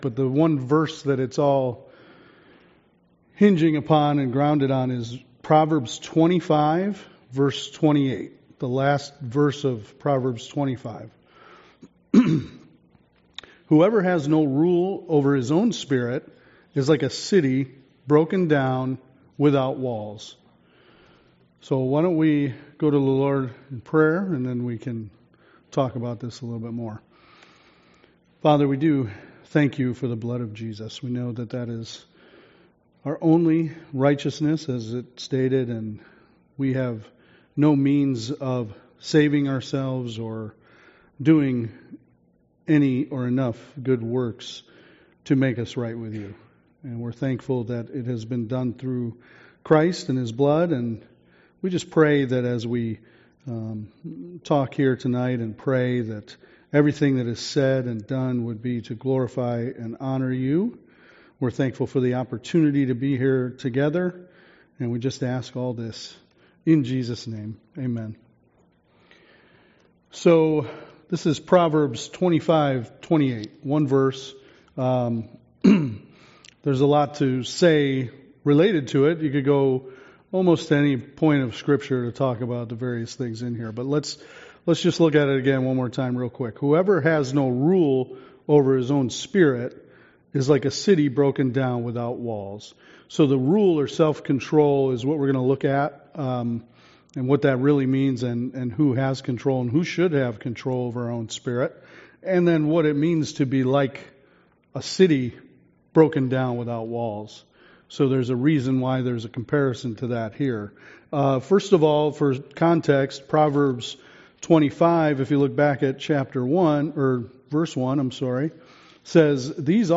Summer Sunday School The Names of God Passage: various Service Type: Sunday School « Sorrento